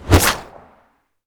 bullet_leave_barrel_03.wav